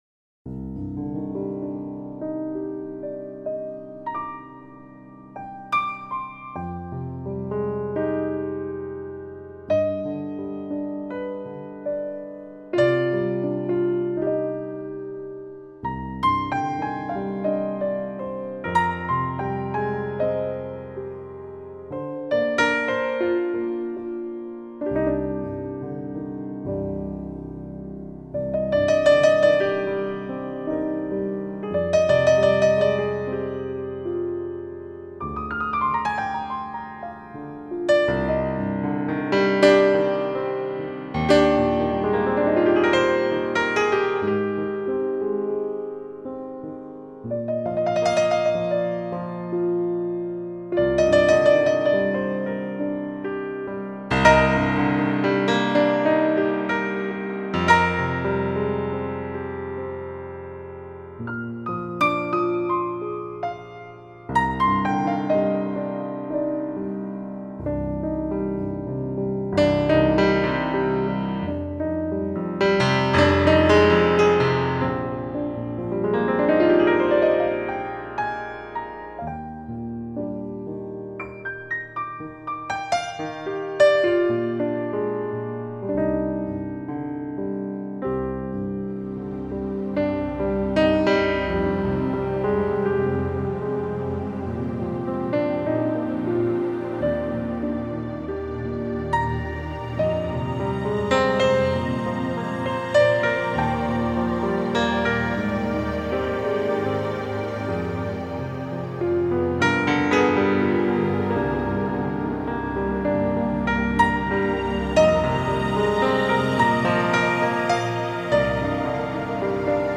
lab Synthogy Ivory Grand Pianos
Steinway---ivory.mp3